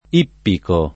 ippico [ & ppiko ] agg.; pl. m. ‑ci